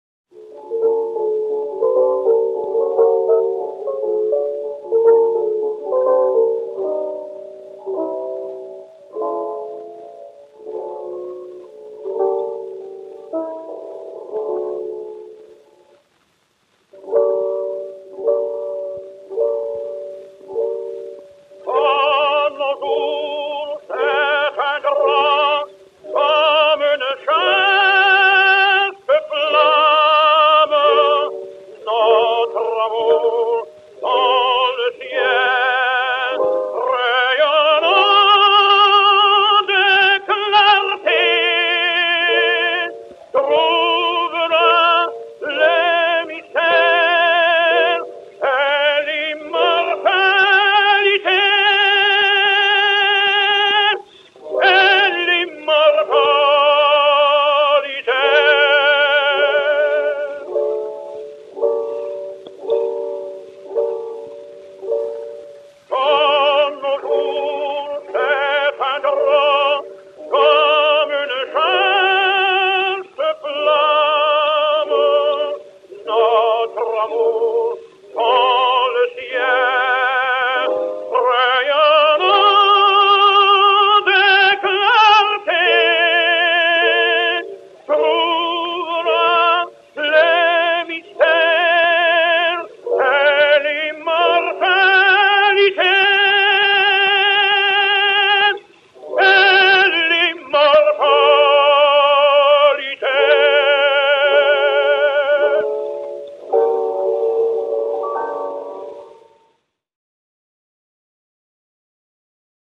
Francesco Tamagno (Jean) et Piano
Disque Pour Gramophone 52684, enr. à Milan en 1903